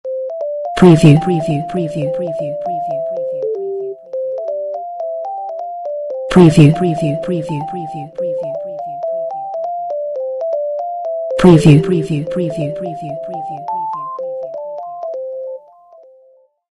Beautiful ringtone